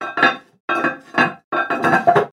Туалет звуки скачать, слушать онлайн ✔в хорошем качестве